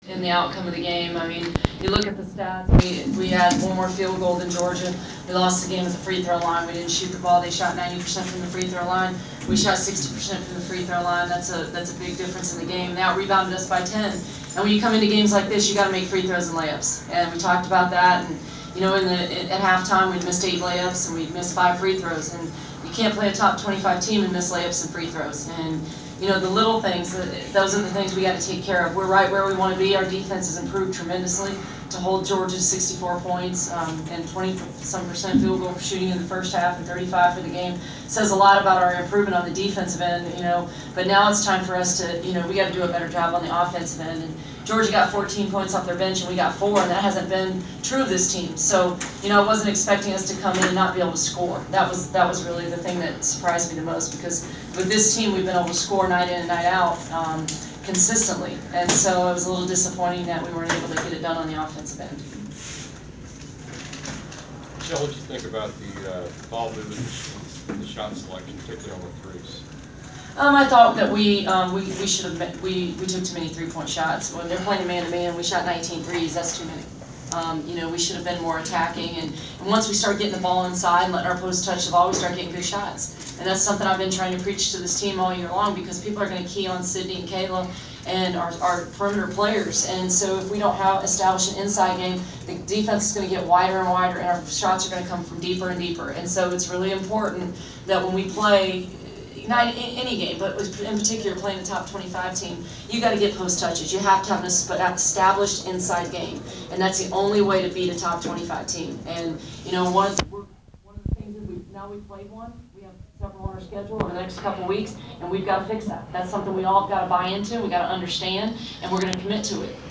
Georgia Tech women’s basketball post-game press conference (11/23/14)